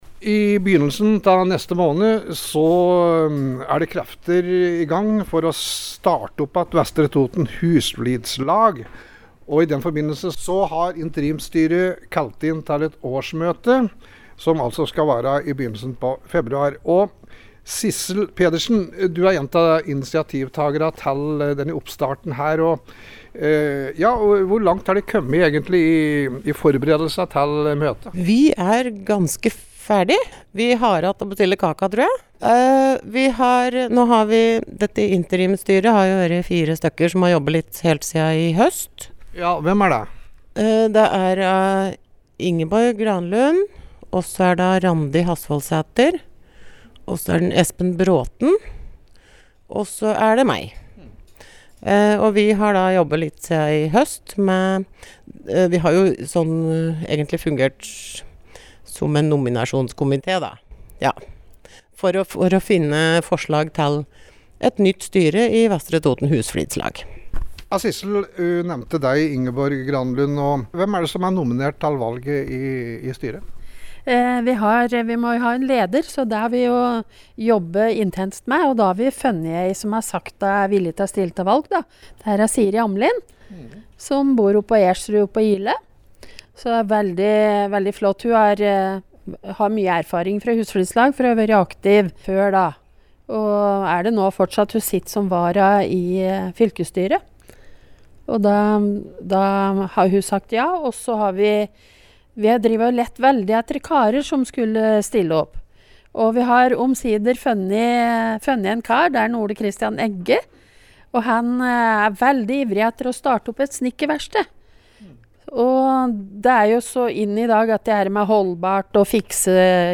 Vo har tatt en prat med to ivrige nøkkelpersoner.